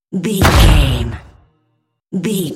Dramatic hit explosion
Sound Effects
heavy
intense
dark
aggressive
hits